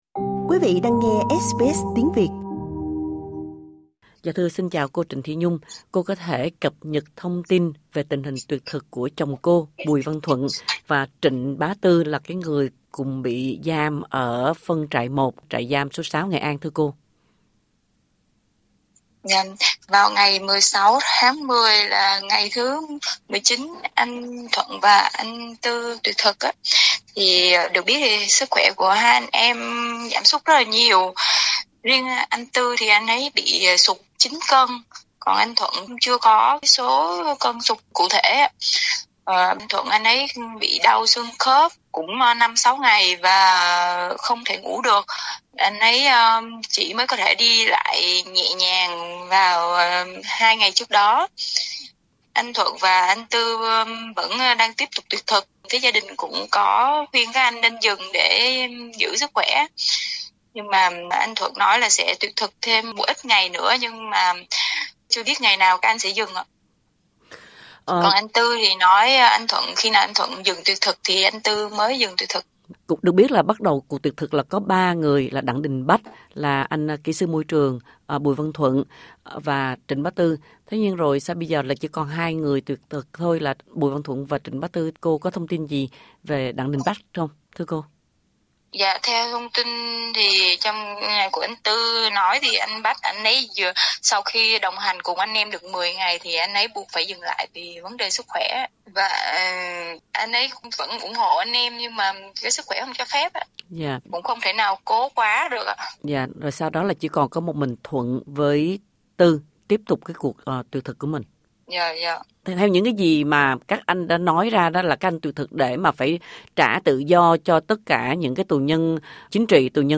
SBS đã liên lạc Đại Sứ Quán Việt Nam tại Úc về những cáo buộc nêu lên trong cuộc phỏng vấn.